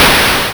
CommError3.wav